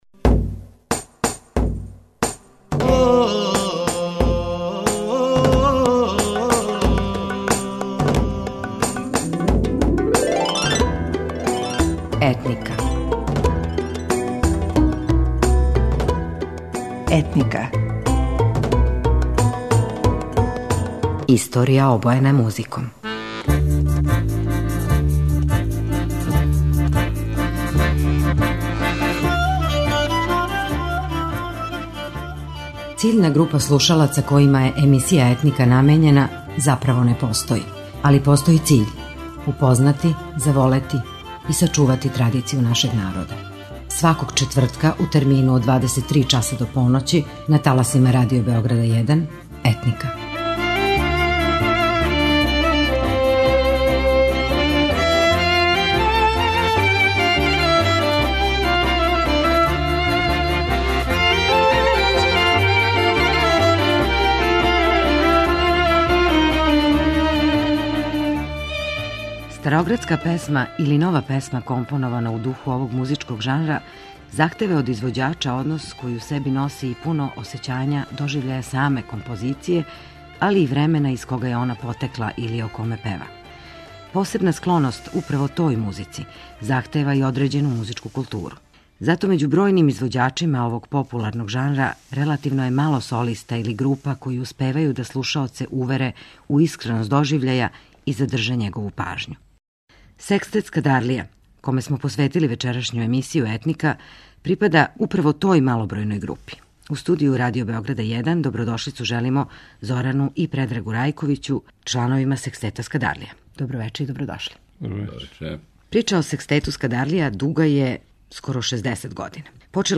Стара градска песма или нова песма компонована у духу овог музичког жанра, захтева од извођача однос који у себи носи и пуно осећање доживљаја саме композиције, али и времена из кога је она потекла или о коме пева.
Секстет "Скадарлија", коме је посвећена емисија, припада малобројној групи извођача који успевају да слушаоце увере у искреност доживљаја и задрже њихову пажњу.